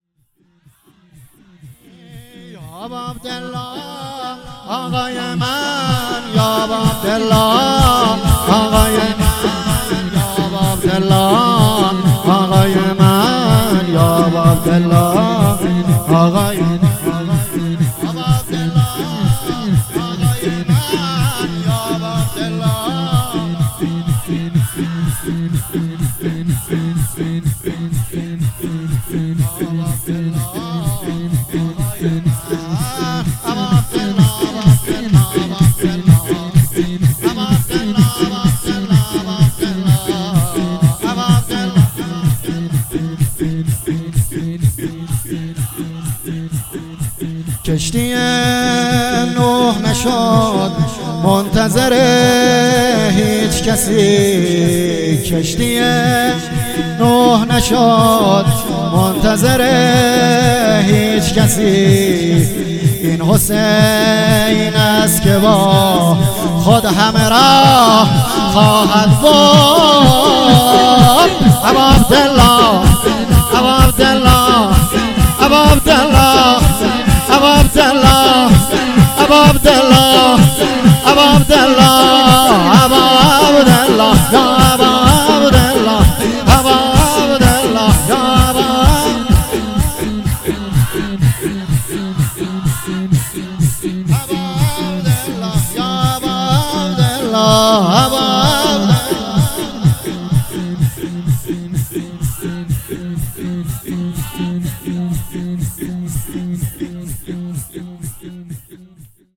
شور
شب اول شهادت امام علی (ع) | هیئت میثاق با شهدا